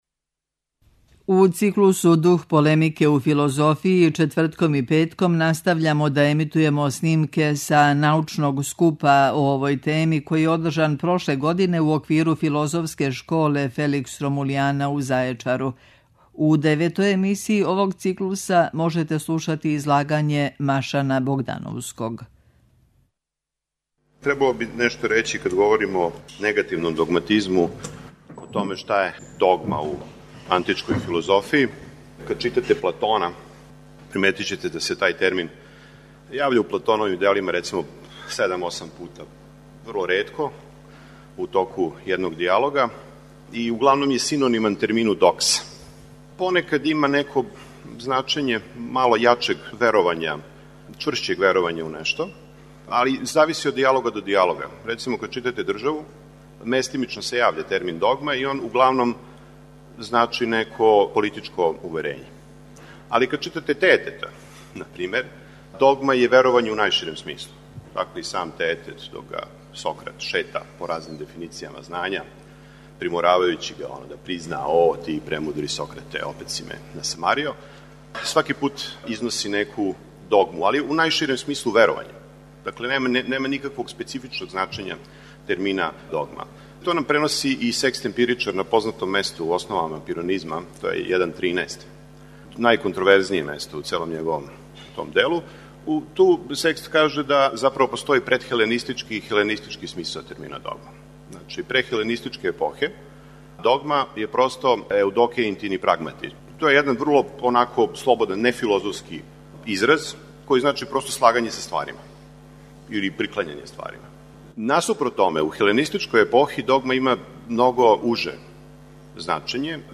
Научни скупови
Прошлог лета у Зајечару одржана је шеснаеста Филозофска школа Felix Romuliana, научни скуп са традицијом дугом 22 године.